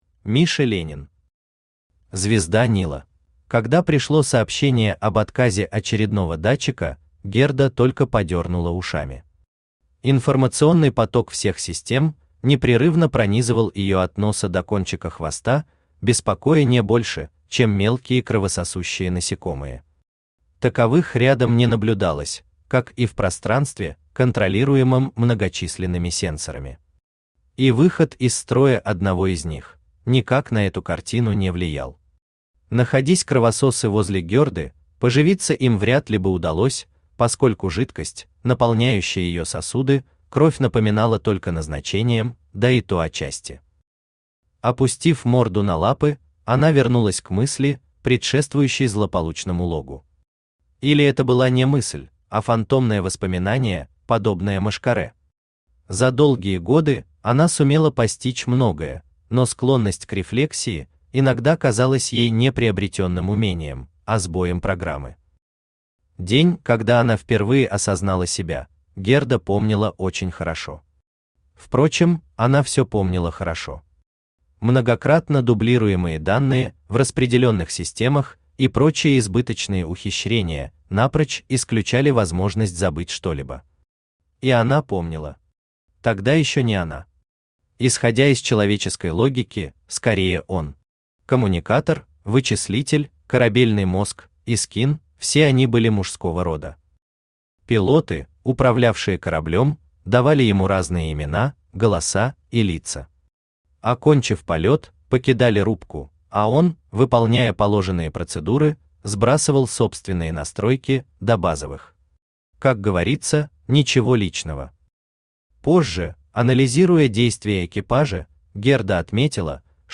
Аудиокнига Звезда Нила | Библиотека аудиокниг
Aудиокнига Звезда Нила Автор Миша Ленин Читает аудиокнигу Авточтец ЛитРес.